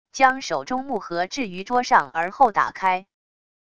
将手中木盒置于桌上而后打开wav音频